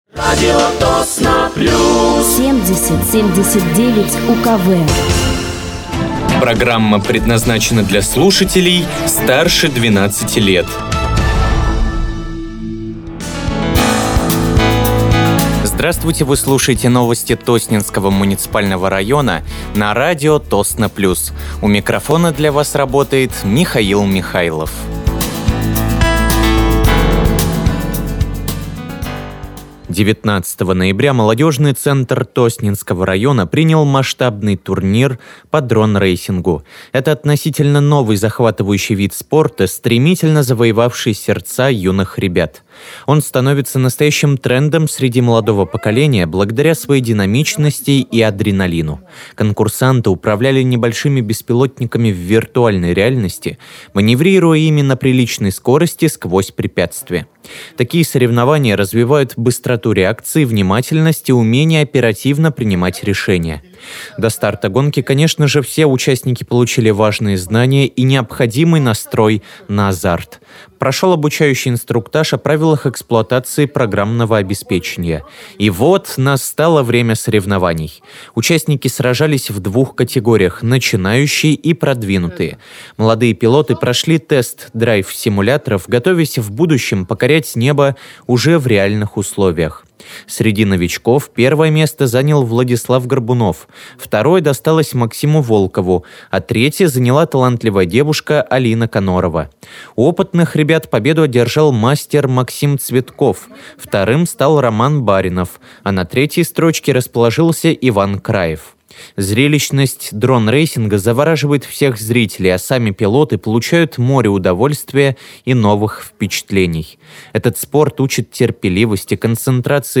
Выпуск новостей Тосненского муниципального района от 24.11.2025
Вы слушаете новости Тосненского муниципального района на радиоканале «Радио Тосно плюс».